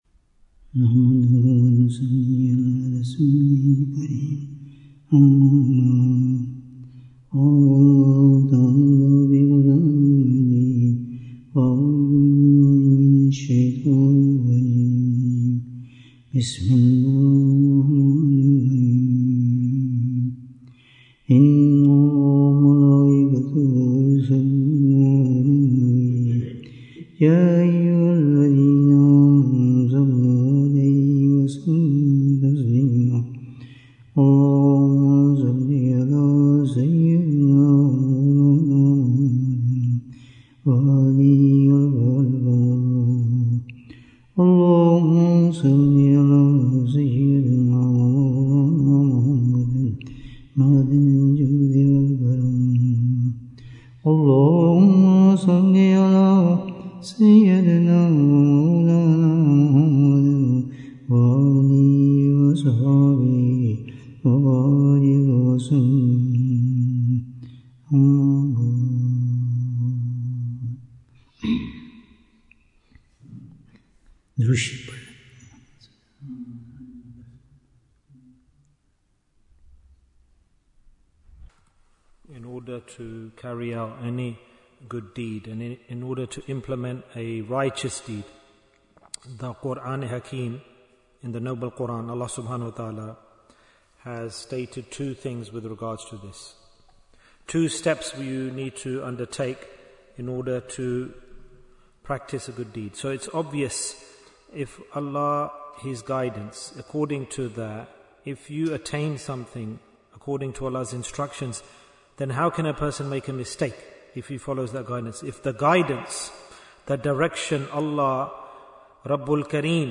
What are the Two Points for Success? Bayan, 80 minutes25th September, 2025